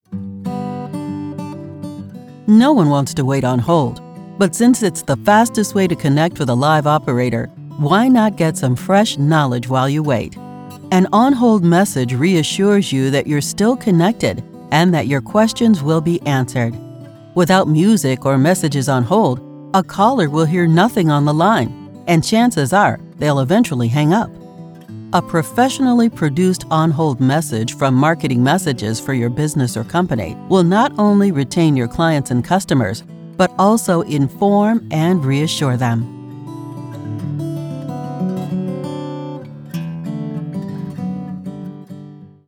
Voice Diversity Sample Audio
African American Voices: